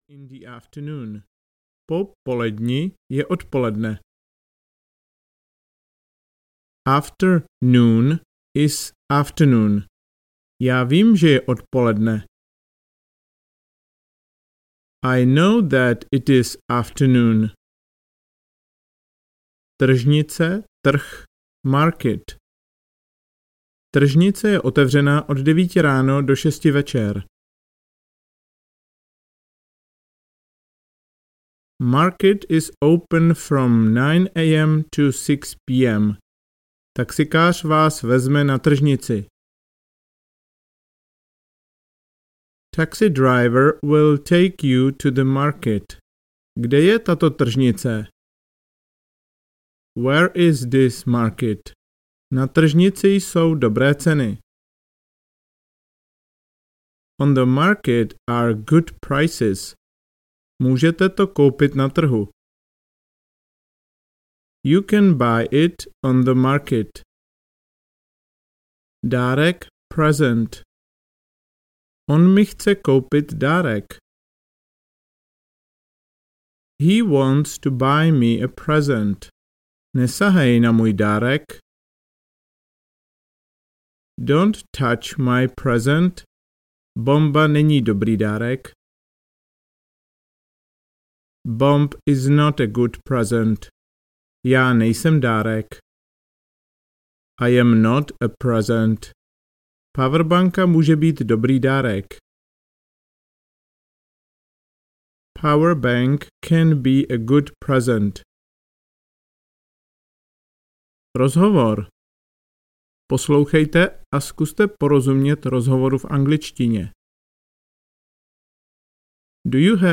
Začátečník ve městě audiokniha
Ukázka z knihy
Zadruhé, celý rozhovor mezi dvěma lidmi, který vám pomůže rozumět mluvené angličtině.